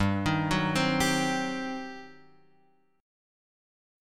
G Major 7th Suspended 4th Sharp 5th